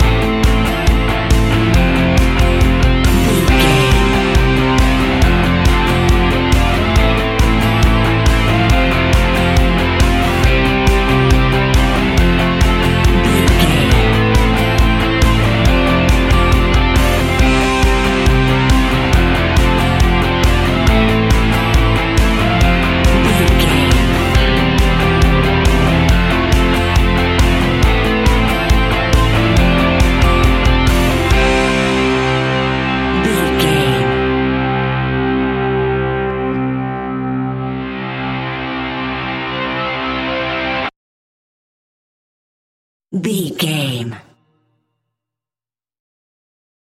Ionian/Major
energetic
driving
heavy
aggressive
electric guitar
bass guitar
drums
uplifting
indie pop rock sound
piano
organ